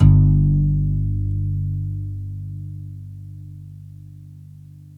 GUITARRON00L.wav